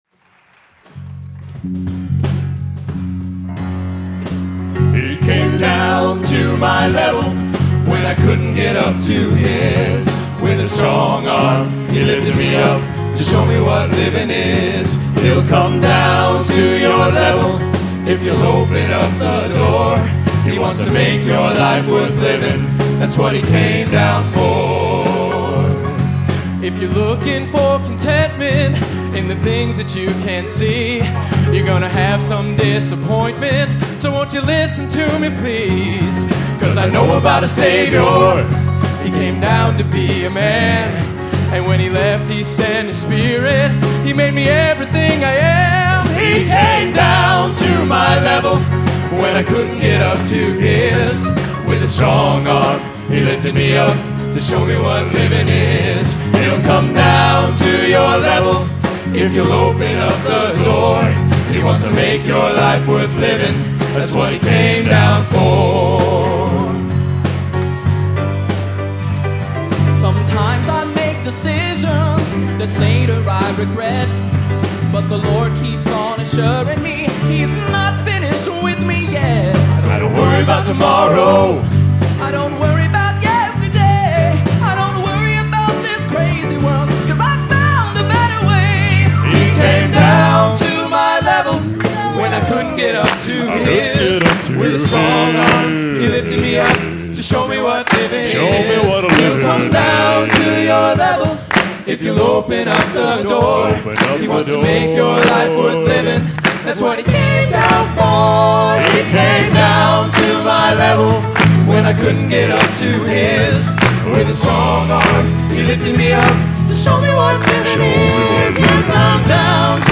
Genre: Gospel | Type: Specialty